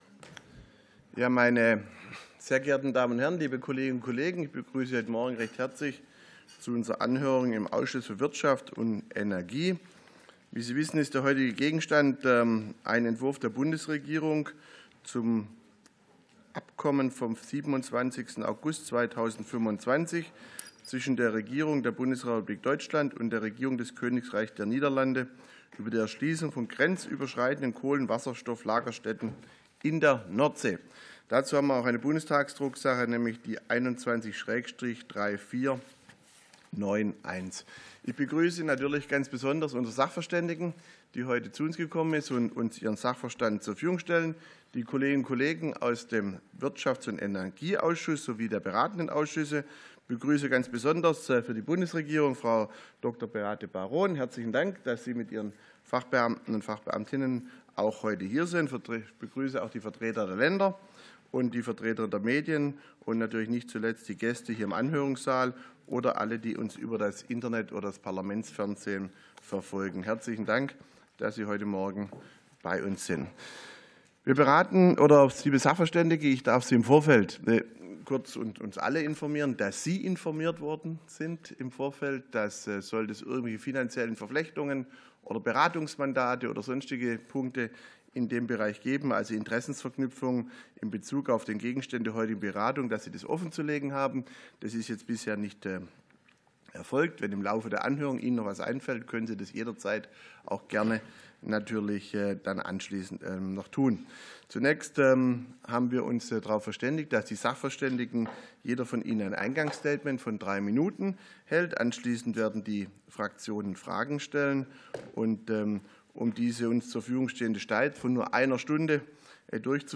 Anhörung des Ausschusses für Wirtschaft und Energie